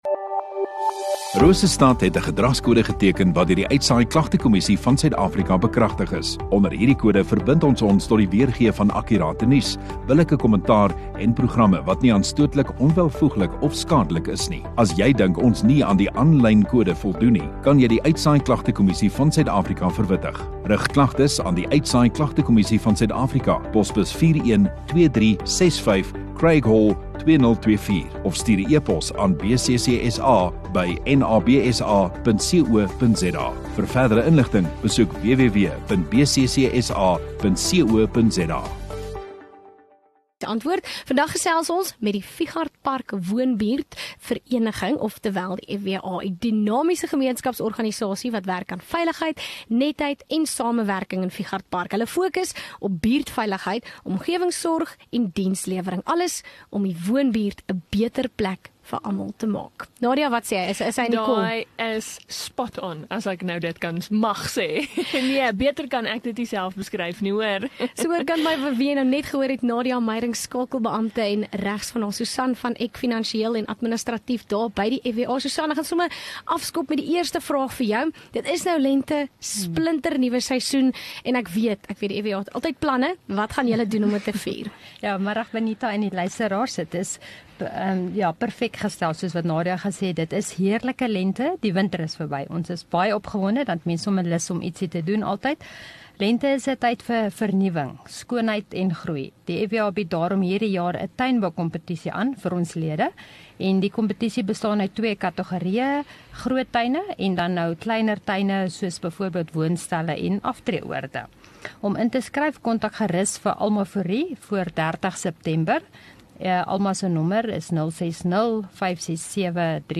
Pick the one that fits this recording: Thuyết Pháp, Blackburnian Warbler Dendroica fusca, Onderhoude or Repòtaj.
Onderhoude